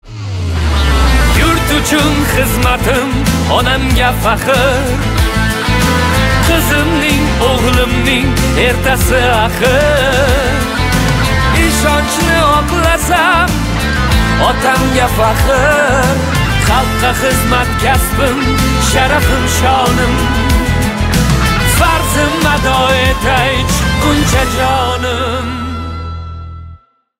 Узбекские # громкие